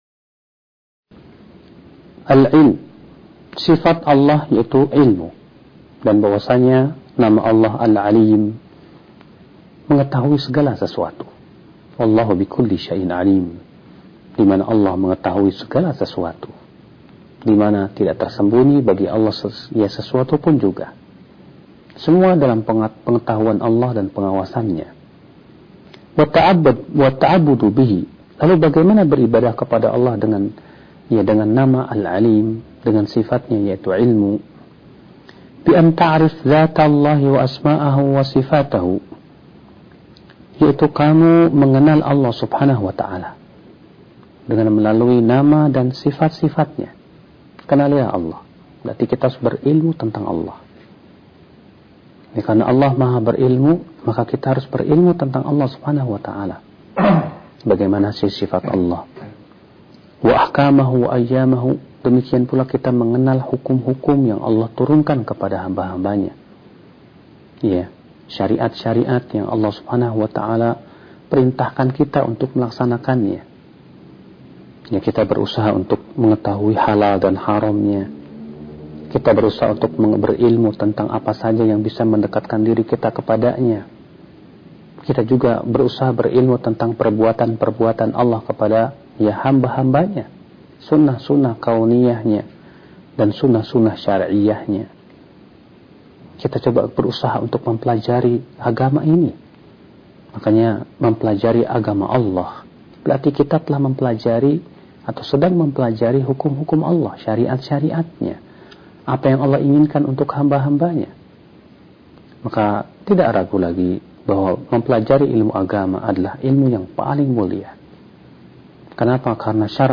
ceramah agama